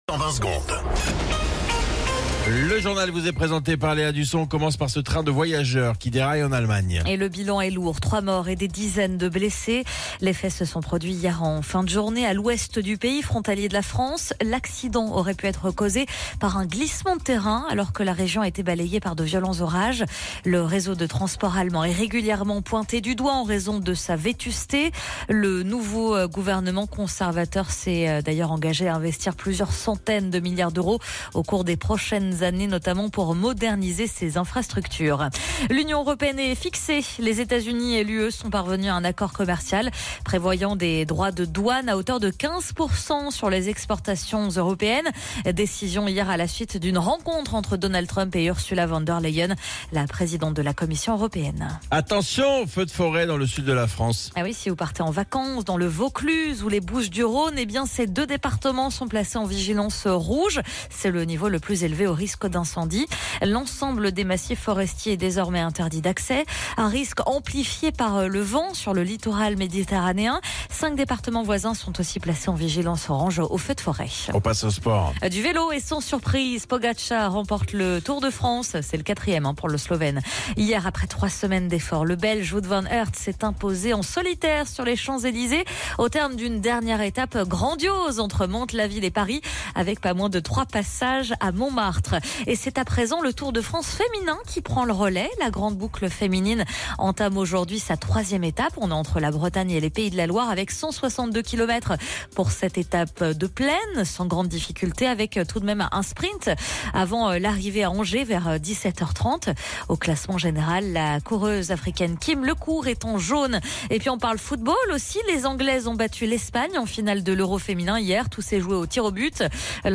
Flash Info National 28 Juillet 2025 Du 28/07/2025 à 07h10 .